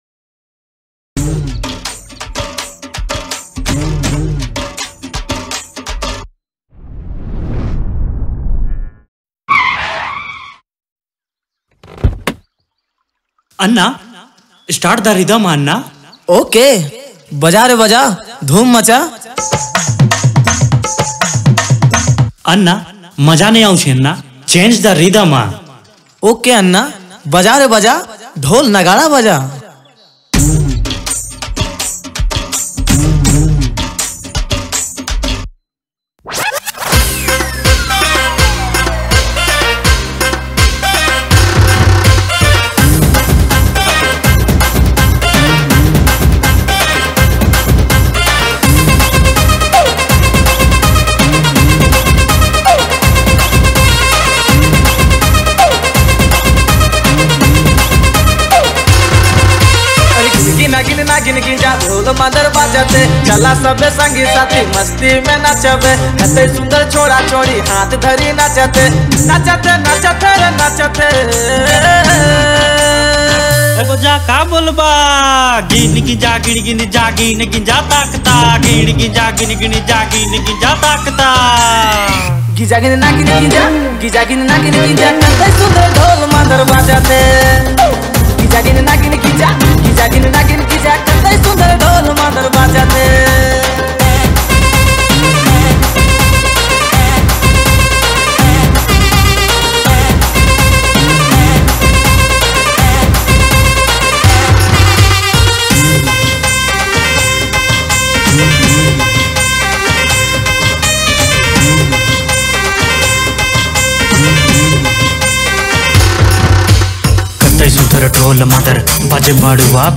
Dj Remixer
New Latest Nagpuri Song